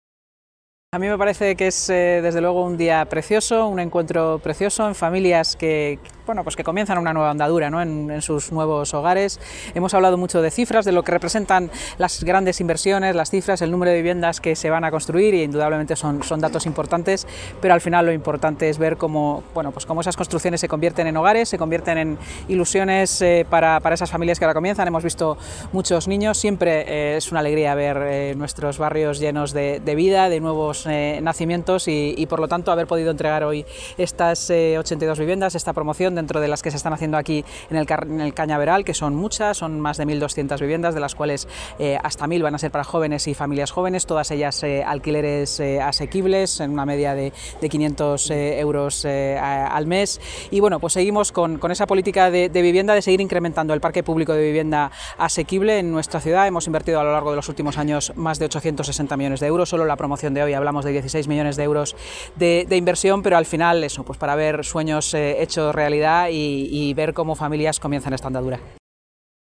Nueva ventana:Declaraciones de la vicealcaldesa de Madrid, Inma Sanz